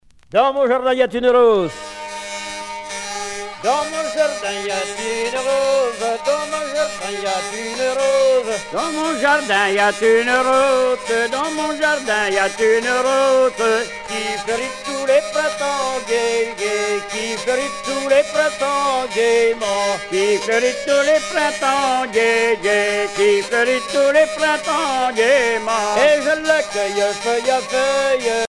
Fonction d'après l'analyste gestuel : à marcher
Genre laisse
Sonneurs de vielle traditionnels en Bretagne
Pièce musicale éditée